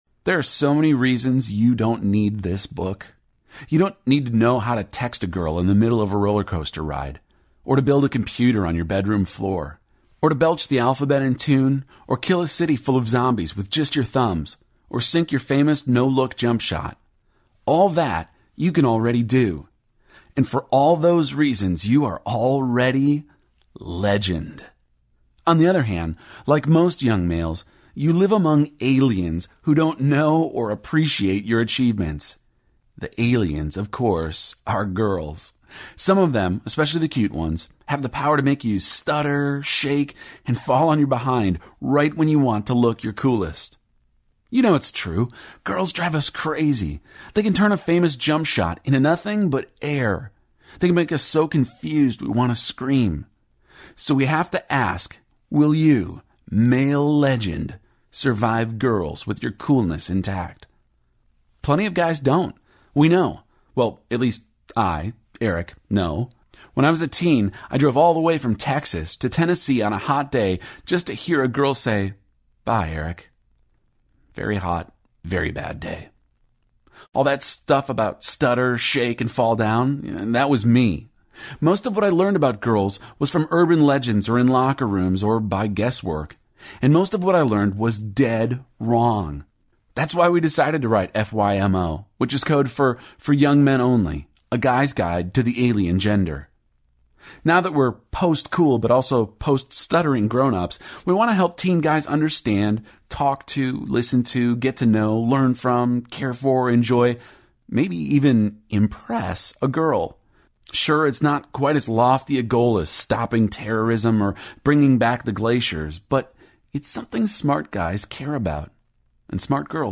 For Young Men Only Audiobook
3.7 Hrs. – Unabridged